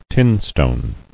[tin·stone]